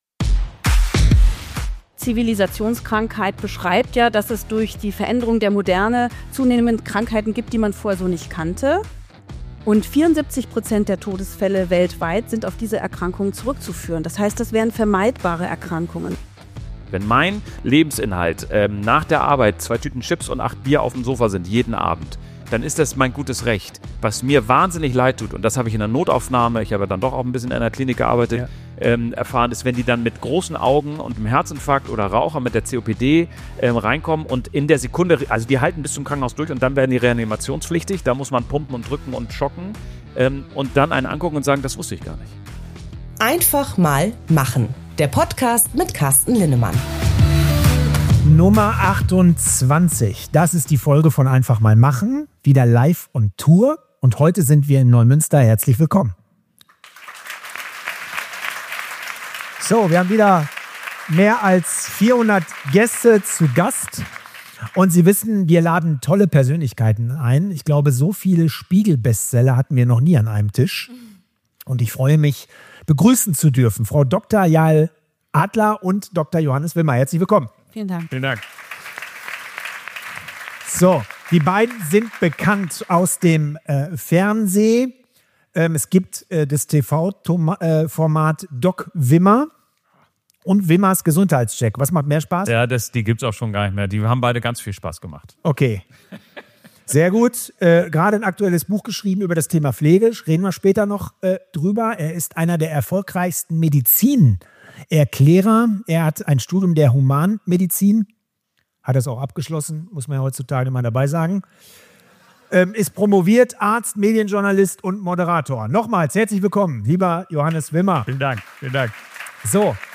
In dieser Live-Folge von Einfach mal machen sprechen Dr. Yael Adler und Dr. Johannes Wimmer offen, pointiert und manchmal unbequem über das, was unsere Gesundheit wirklich beeinflusst: Ernährung, Zucker, Schlaf, Stress, Bewegung – und unsere eigene Verantwortung.